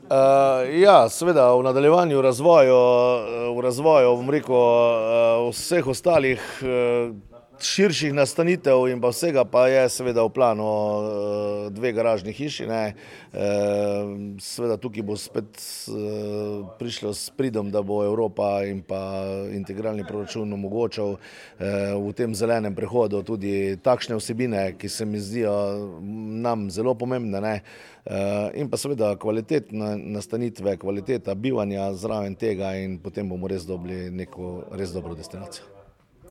V Mislinji je včeraj potekala okrogla miza o tem, kako se izogniti pastem množičnega turizma na Pohorju.